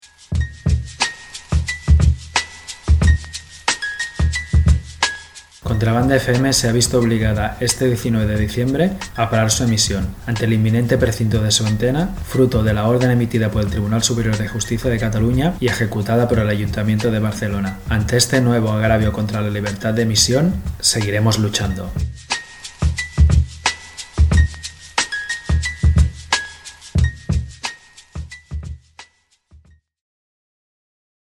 falca-antena_curta.mp3